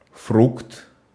Ääntäminen
IPA: /ˈfrɵkt/